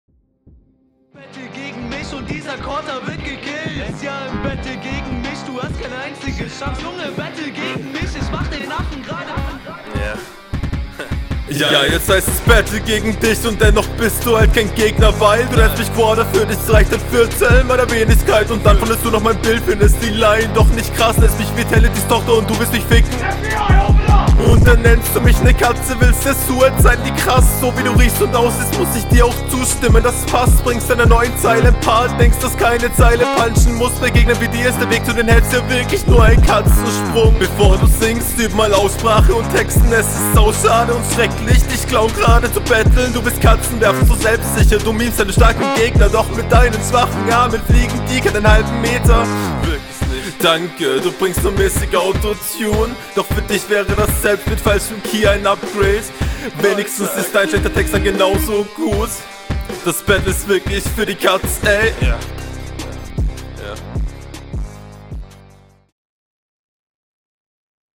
Flowlich find ich dich noch bisschen unsicherer, das ist teilweise noch …
uff, da greifst du ja sogar dieses "battle gegen mich" auf. ja killer intro. stimmeinsatz …